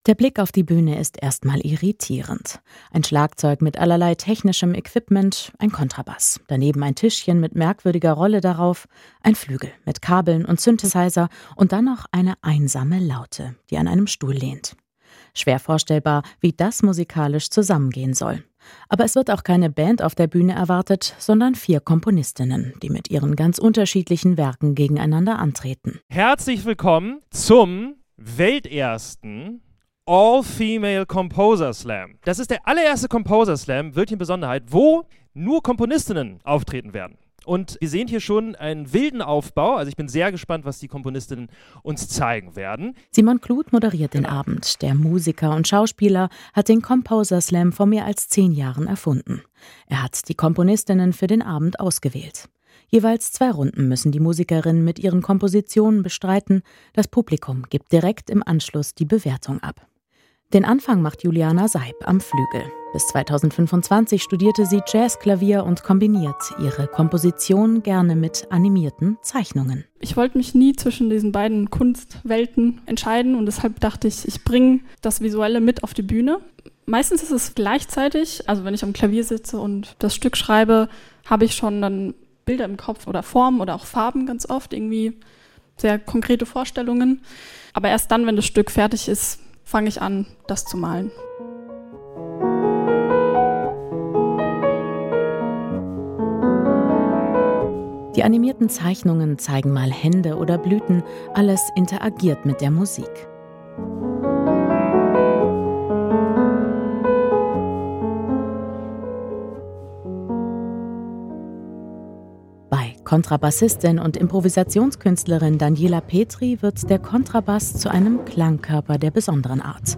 Die Musik mutet verträumt an. Sanfte Jazzklänge, die die Gedanken in eine bunte Welt entführen, wo sie mit ihren Illustrationen verschmelzen.
Ihre melancholische und doch lebendige Musik, bei der sie auch zum Teil Laute mitsingt und auch ihre Stimme als Instrument nutzt, berührt das Publikum sichtbar.
Ganz entscheidend beim Composer Slam: Im Mittelpunkt steht Instrumentalmusik – also im Gegensatz zu anderen Slam-Formaten ist der Einsatz von Text nicht erlaubt.